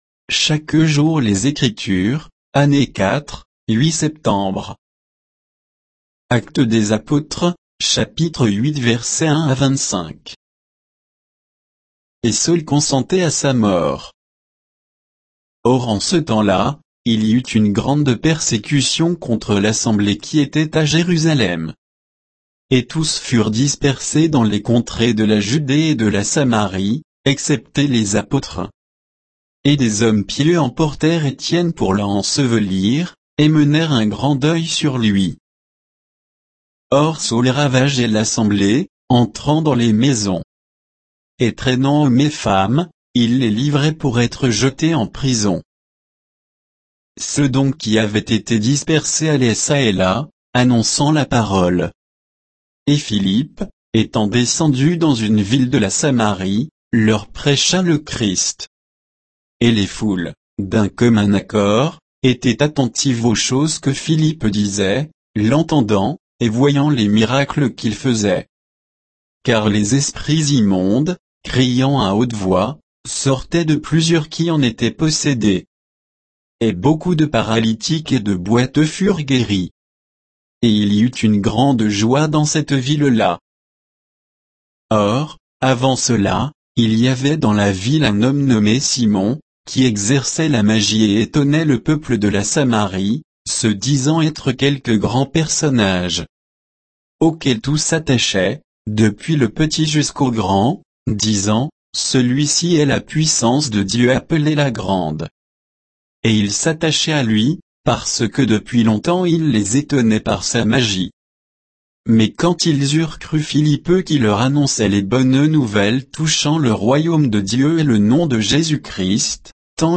Méditation quoditienne de Chaque jour les Écritures sur Actes 8